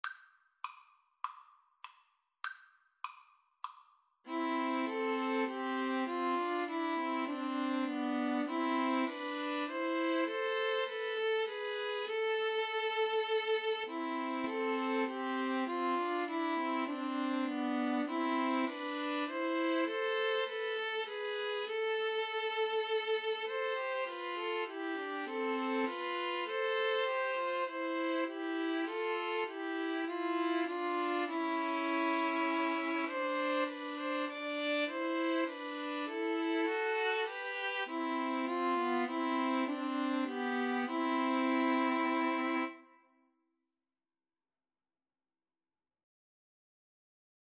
Play (or use space bar on your keyboard) Pause Music Playalong - Player 1 Accompaniment Playalong - Player 3 Accompaniment reset tempo print settings full screen
C major (Sounding Pitch) (View more C major Music for String trio )